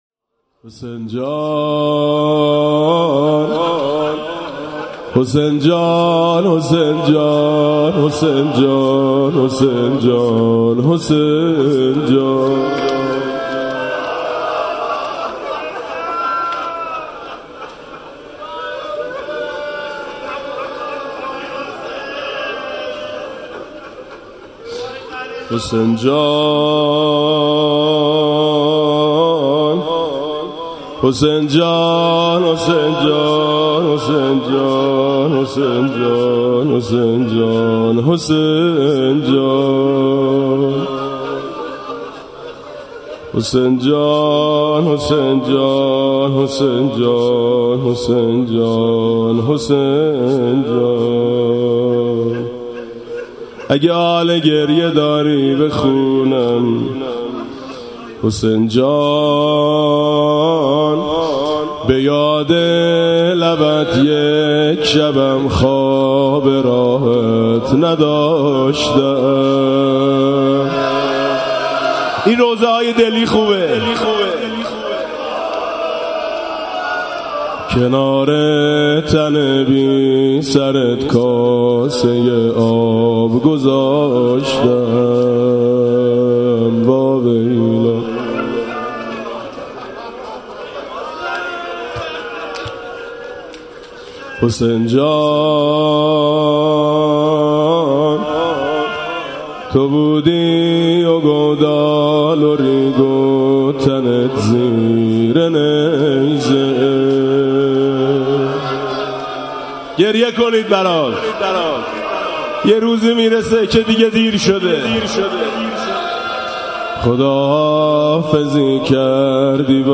[آستان مقدس امامزاده قاضي الصابر (ع)]
مناسبت: قرائت مناجات شعبانیه
با نوای: حاج میثم مطیعی
به یاد لبت یک شبم خواب راحت نداشتم (روضه)